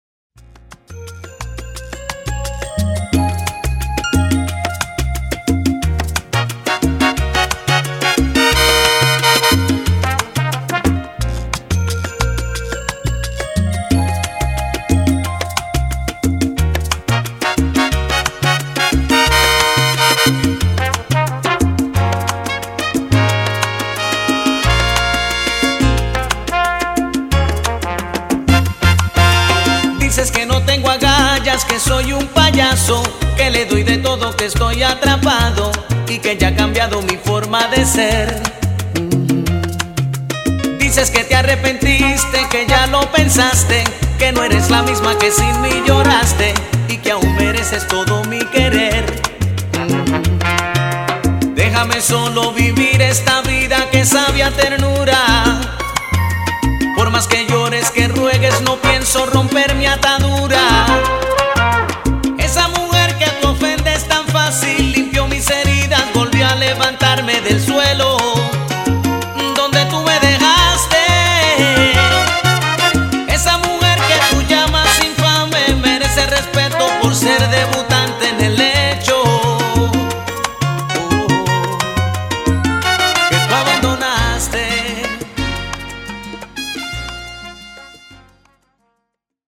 Salsa
salsa.mp3